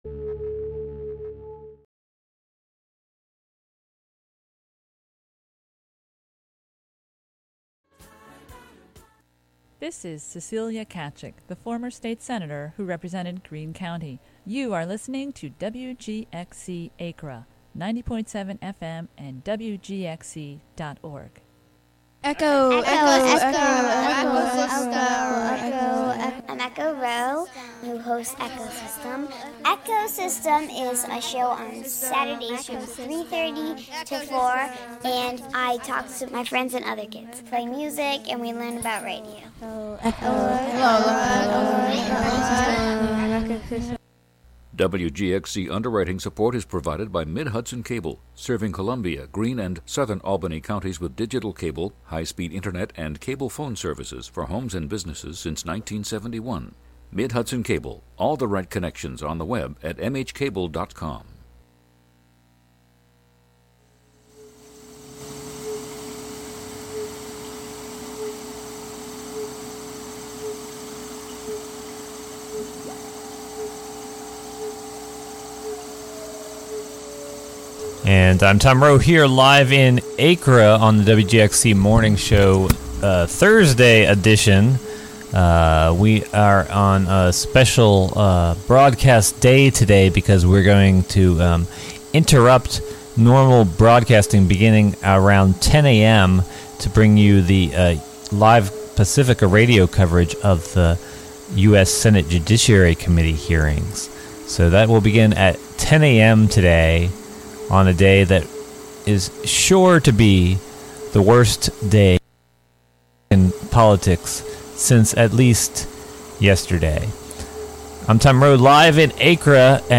The WGXC Morning Show is a radio magazine show featuring local news, interviews with community leaders and personalities, a rundown of public meetings, local and regional events, with weather updates, and more about and for the community.